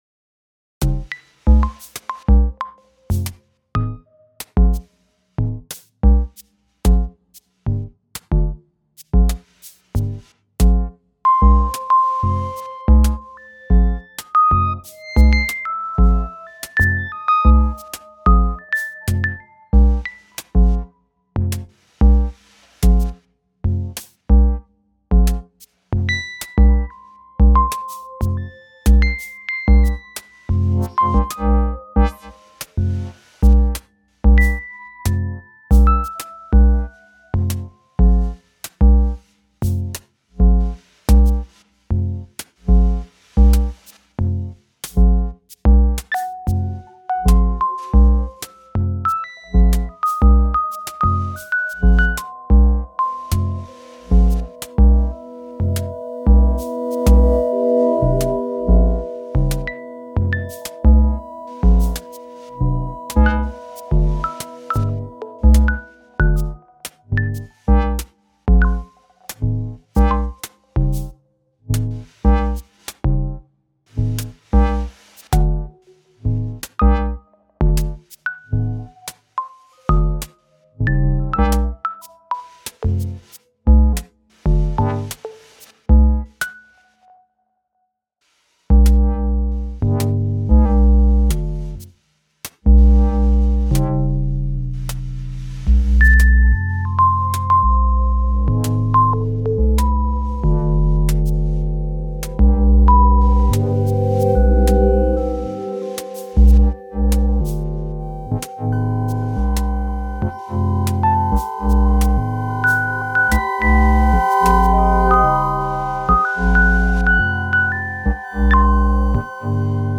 The first one is an euclidean groove with some jazzy (at least in my ears) generative lead voice and some additive pad sounds. Those voices and patterns are all generated in vcv with some hands-on midi-controlling. After multitracking a much too long performance I edited and mixed in reaper and added a bit of extra percussion elements.